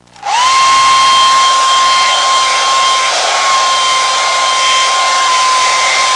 Hairdryer Sound Effect
Download a high-quality hairdryer sound effect.
hairdryer.mp3